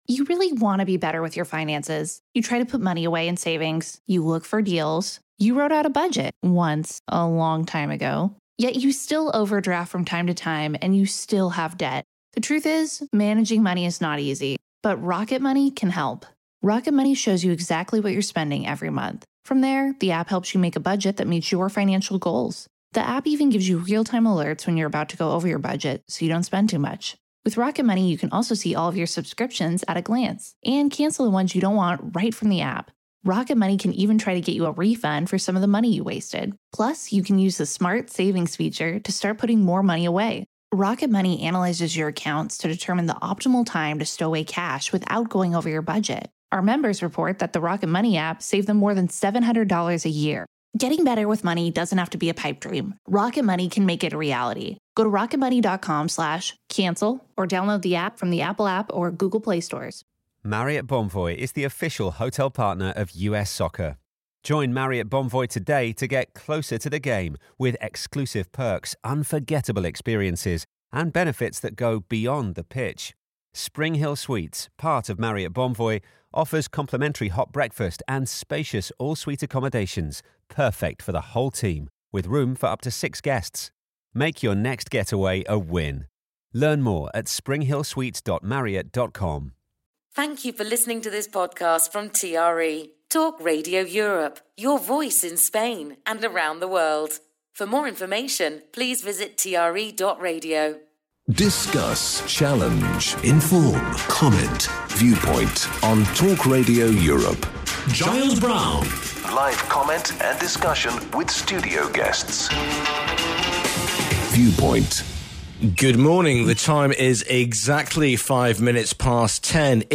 his panel of guests